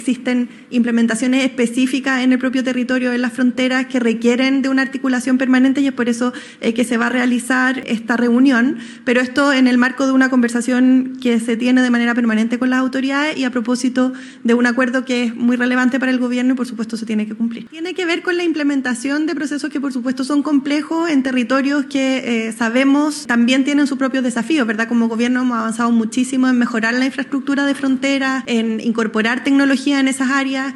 Por su parte, la ministra (S) secretaria general de Gobierno, Aisén Etcheverry, explicó que la implementación del acuerdo requiere de una articulación permanente debido a los desafíos que presentan las fronteras.
CUNA-VOCERA-2.mp3